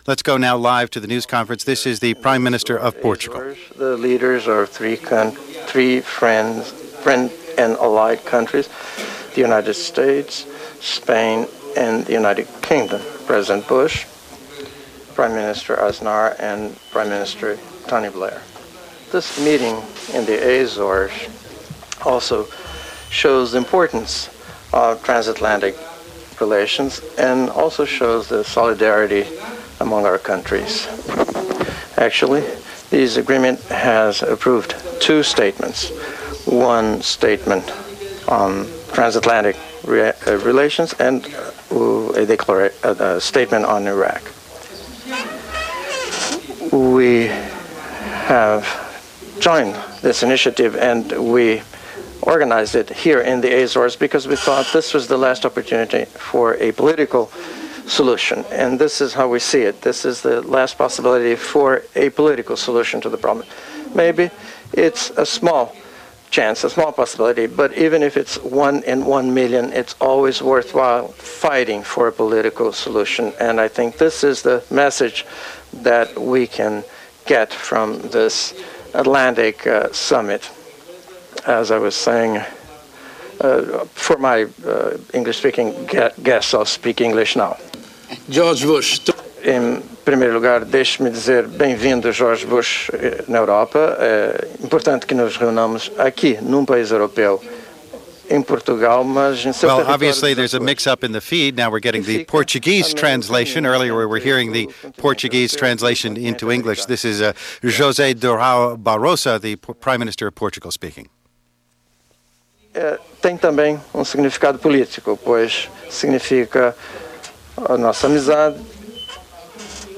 Press Conference from Summit in the Azores